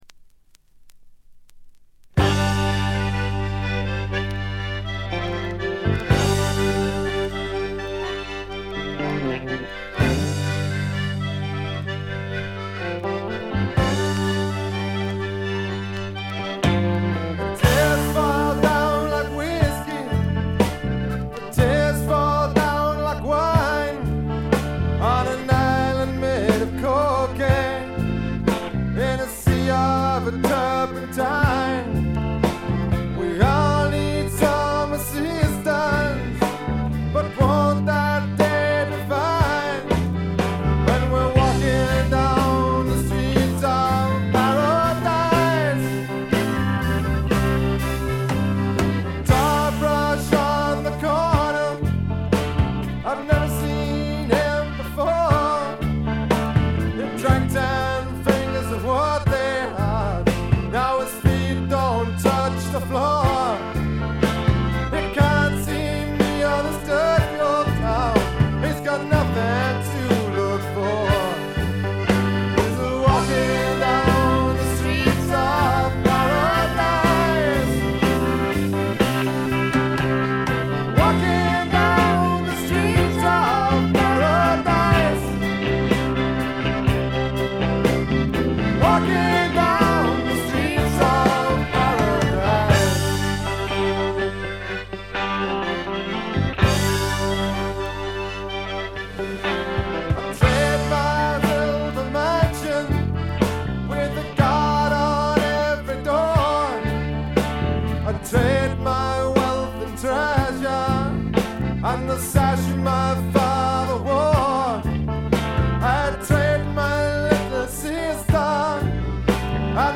軽微なチリプチ少々。
そのせいかどうかはわかりませんが、全体に凛とした美しさと張りつめた緊張感が際立ちます。
試聴曲は現品からの取り込み音源です。